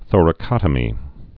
(thôrə-kŏtə-mē)